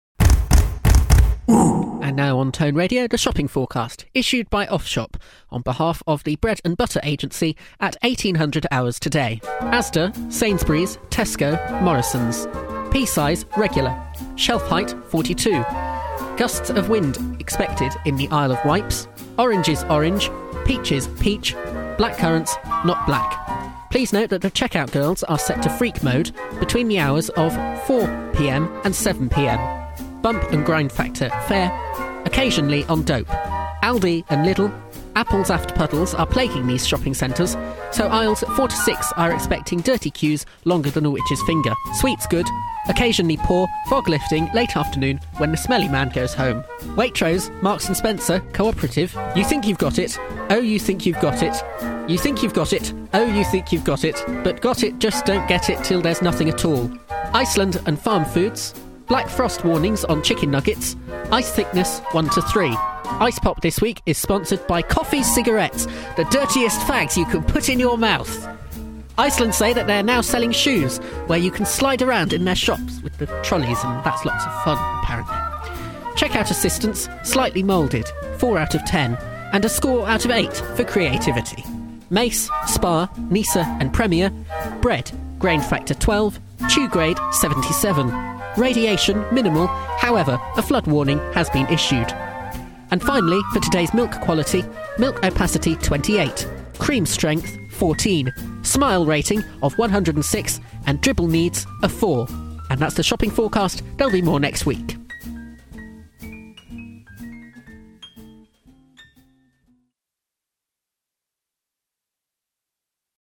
Previously broadcast on In The Dark and The Johnny Robinson Affair. Contains some strong language.